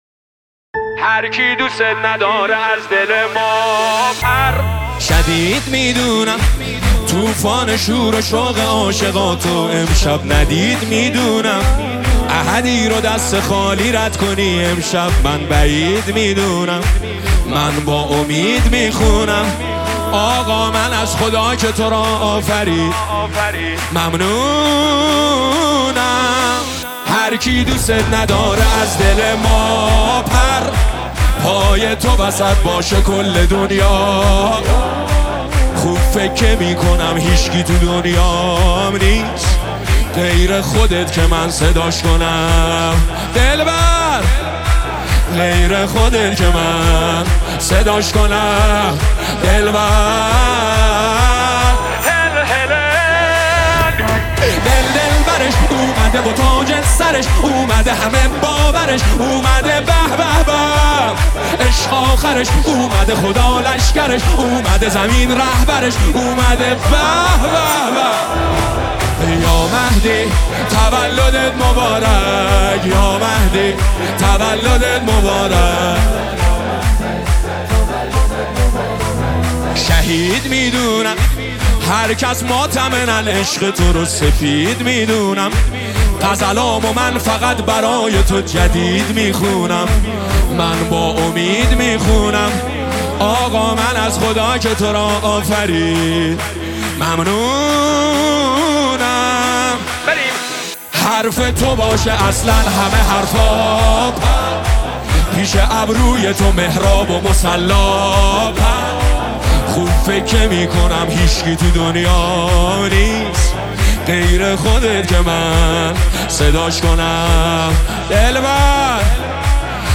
مولودی نیمه شعبان